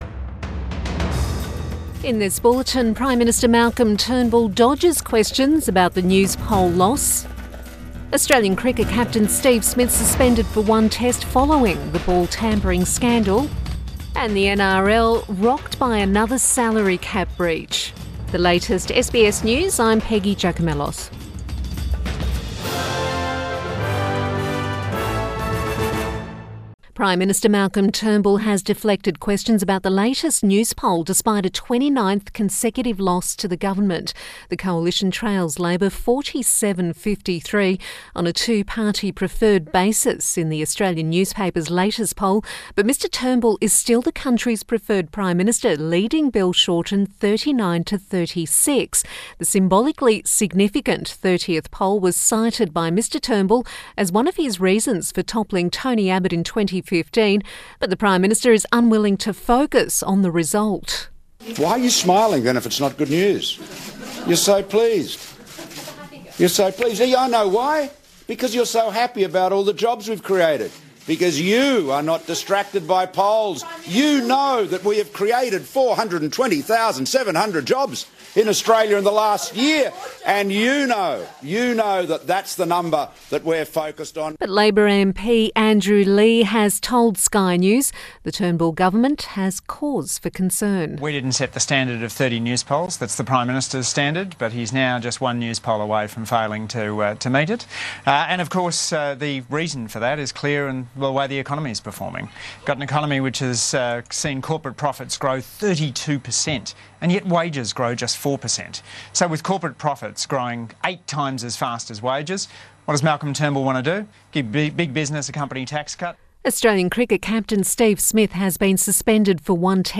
PM Bulletin 26 March